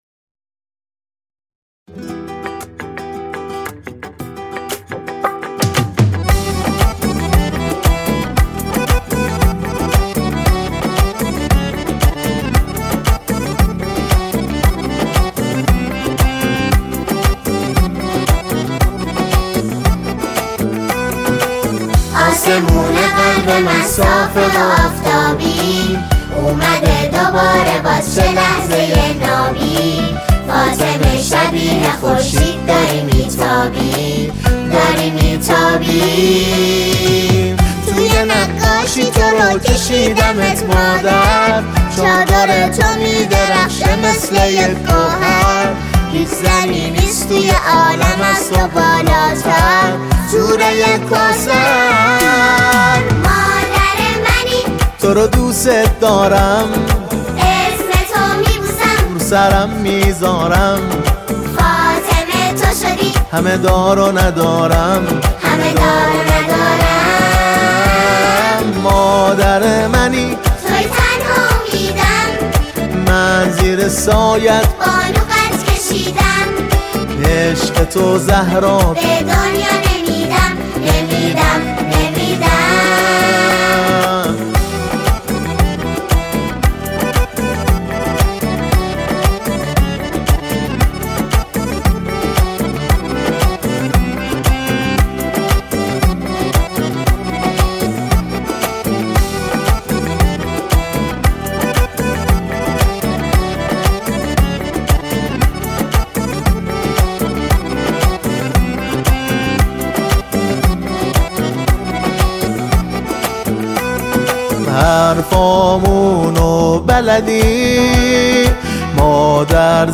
ولادت امام علی (ع)